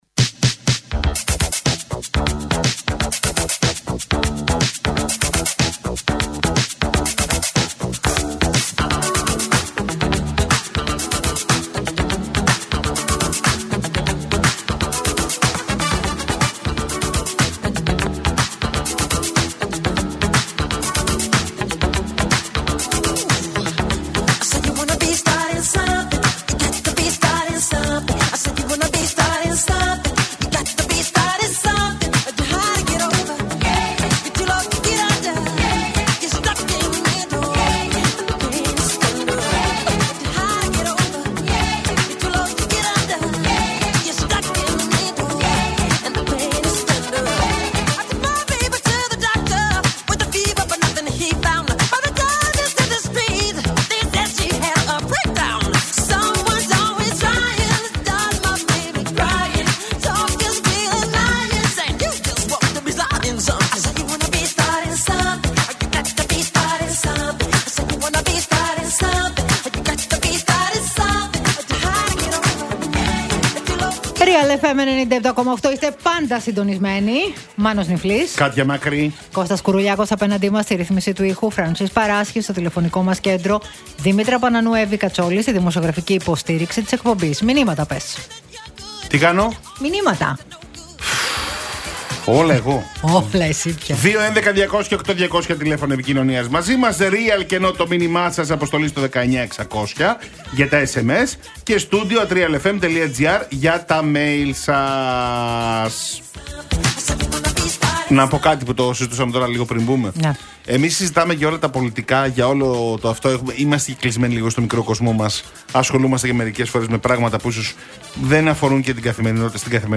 Η συνεδρίαση της Κοινοβουλευτικής Ομάδας του κυβερνώντος κόμματος, την Τετάρτη, και τα μηνύματα που οι πολίτες έστειλαν στη Νέα Δημοκρατία, ήταν το αποκλειστικό θέμα συζήτησης στη συνέντευξη του υπουργού Επικρατείας Μάκη Βορίδη στο “Realfm 97,8”